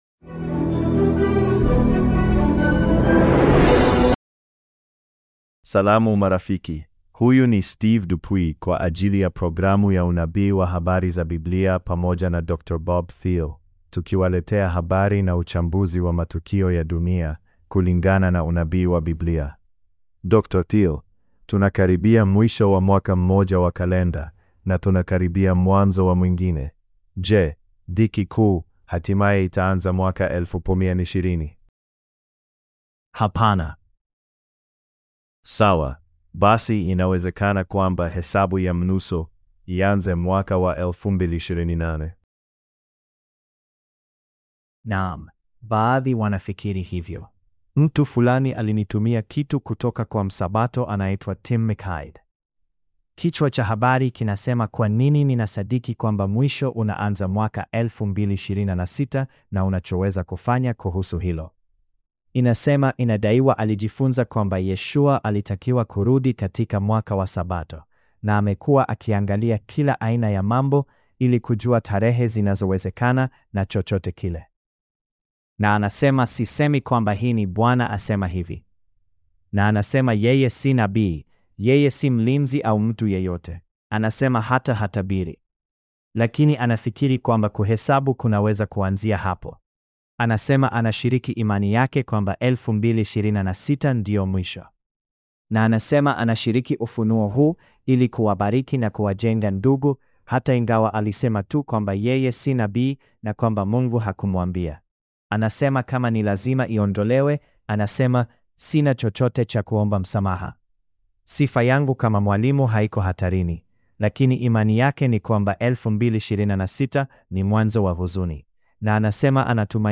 Swahili Sermonette – Bible Prophecy News